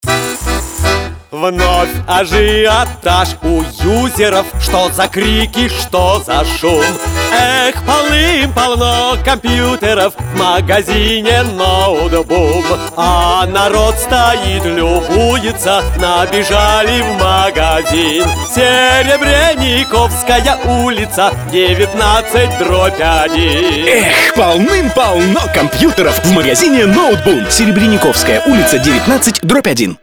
Сценарий радиоролика
баян